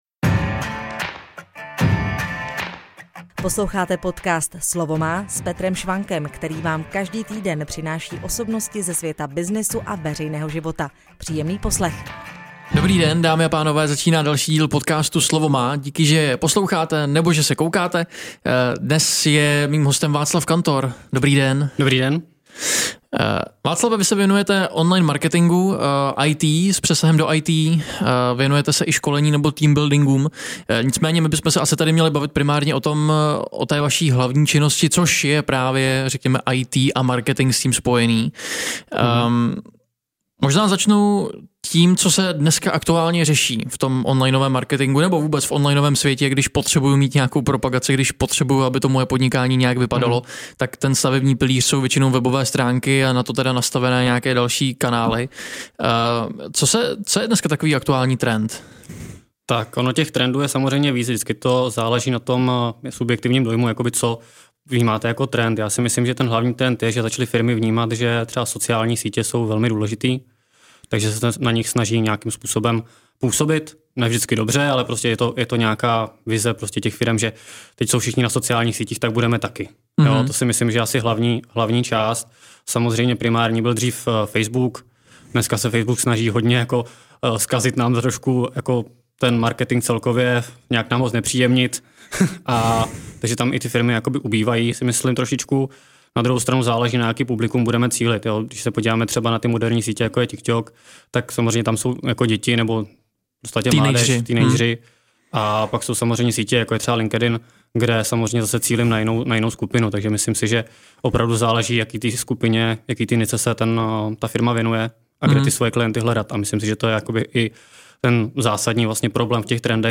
I proto jsme se v rozhovoru tentokrát zaměřili hlavně na to, co by řeší většina majitelů firem: jak přilákat nové zákazníky z internetu.